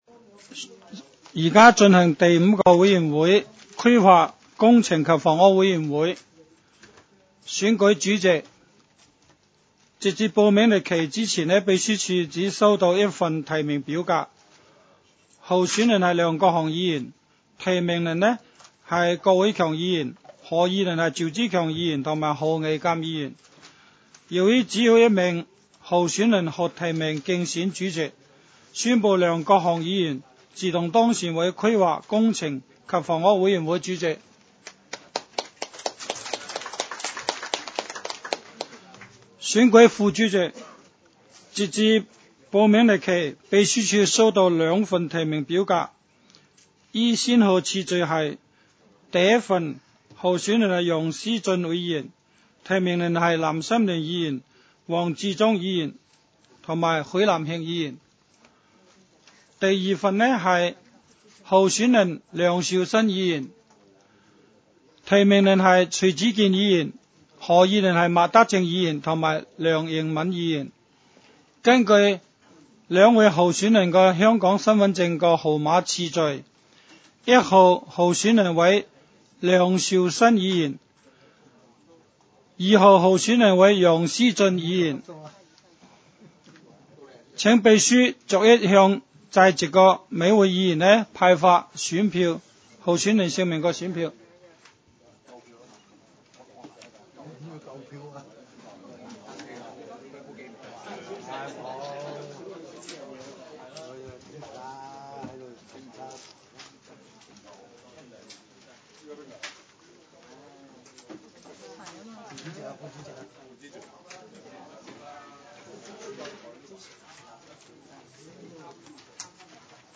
委员会会议的录音记录